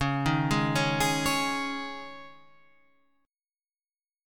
C#sus2 Chord